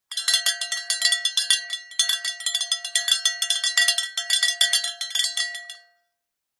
Звуки коровы
Звон коровьего колокольчика